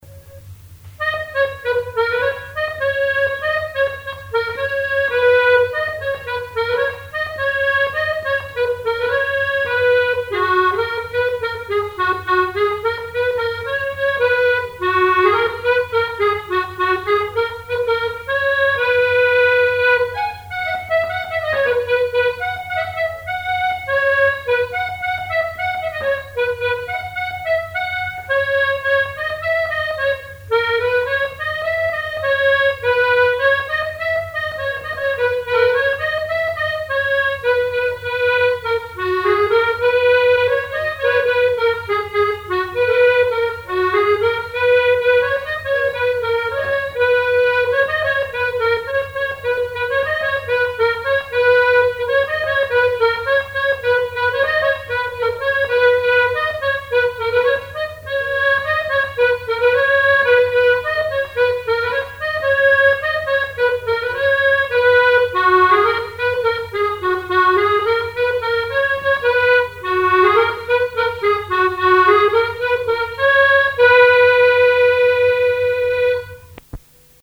branle : Saute sur moe Marie, saute
Catégorie Pièce musicale inédite